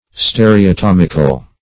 Search Result for " stereotomical" : The Collaborative International Dictionary of English v.0.48: Stereotomic \Ste`re*o*tom"ic\, Stereotomical \Ste`re*o*tom"ic*al\, a. Of or pertaining to stereotomy; performed by stereotomy.